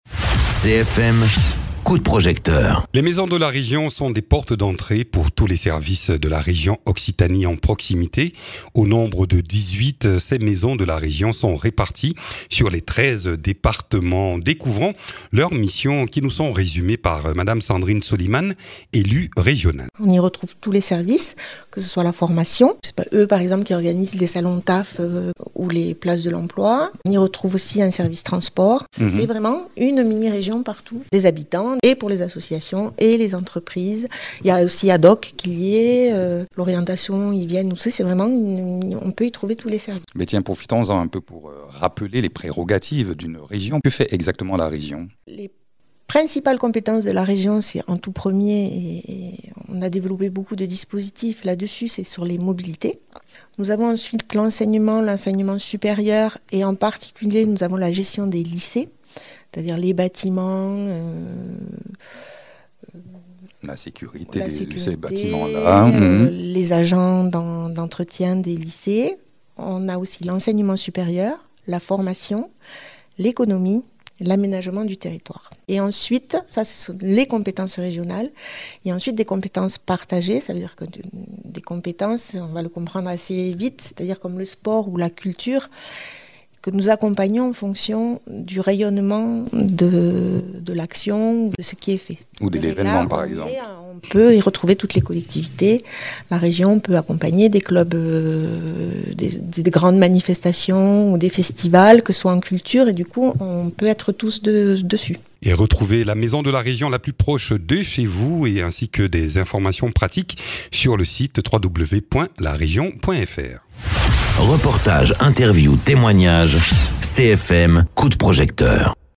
Interviews
Invité(s) : Sandrine Soliman, élue de la région Occitanie.